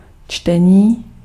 Ääntäminen
IPA : /ˈriːdɪŋ/